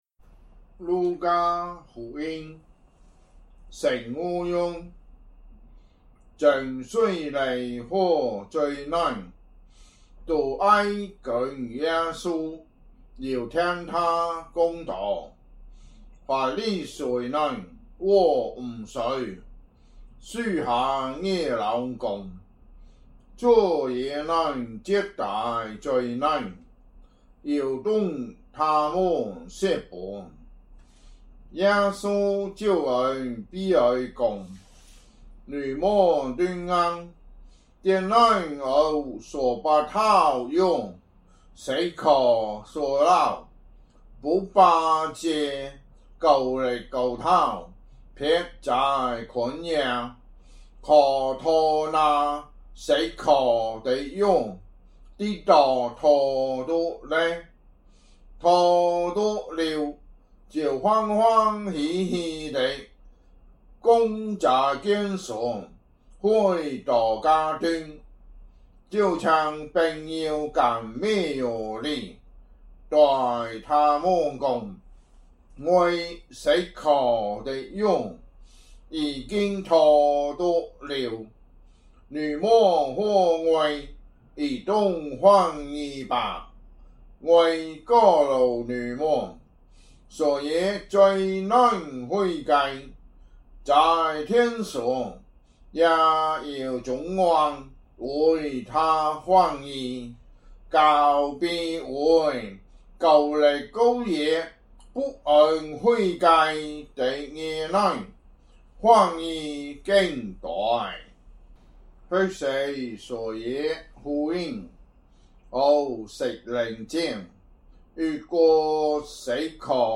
福州話有聲聖經 路加福音 15章